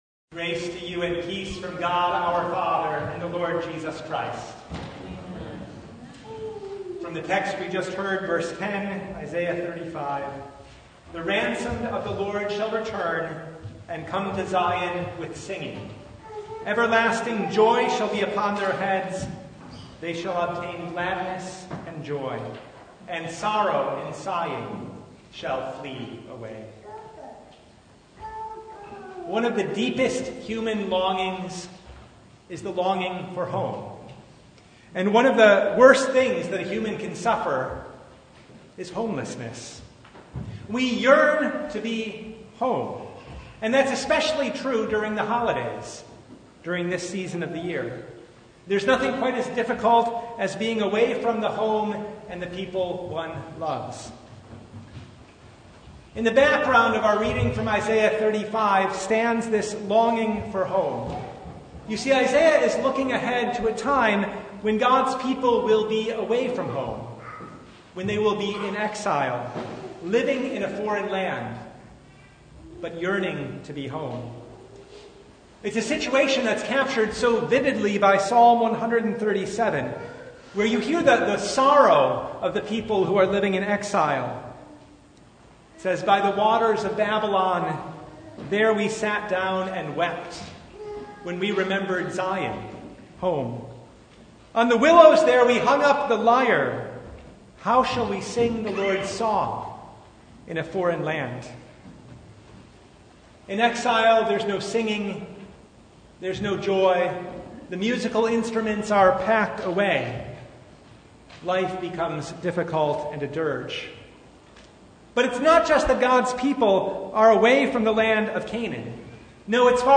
Service Type: Advent Vespers